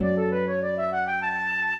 flute-harp
minuet11-4.wav